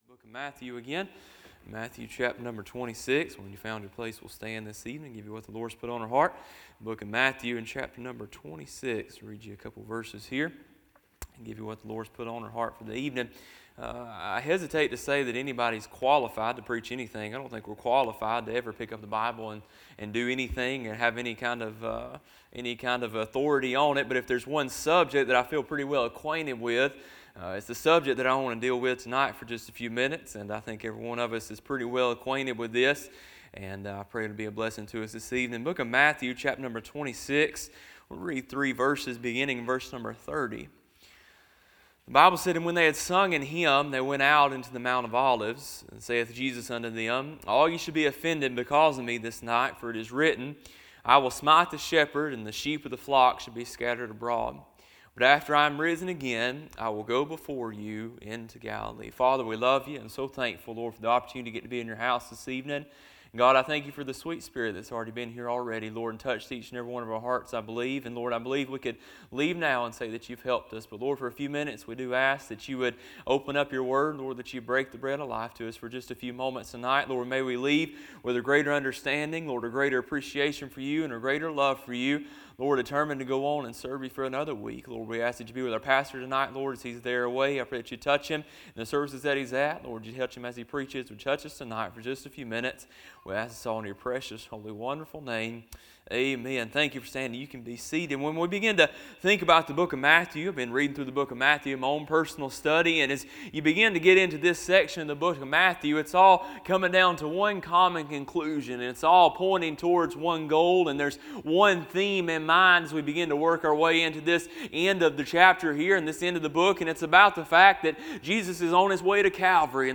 Sermons - Bible Baptist Church